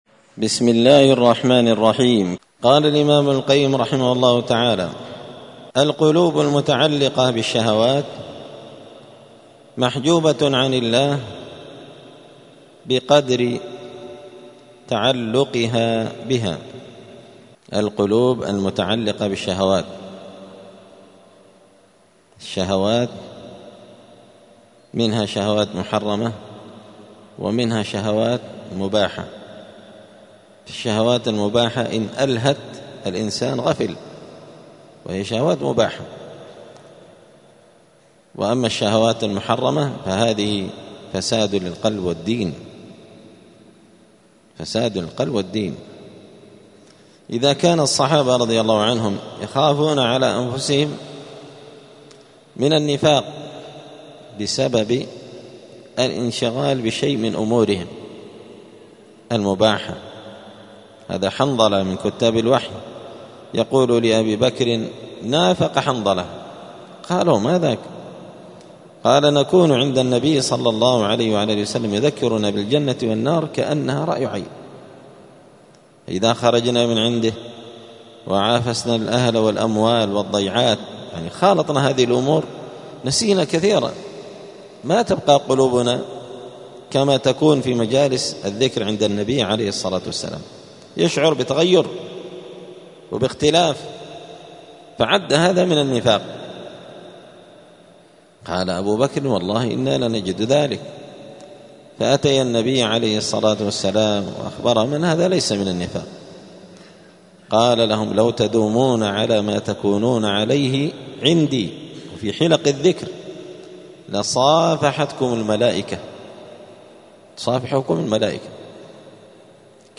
الدروس الأسبوعية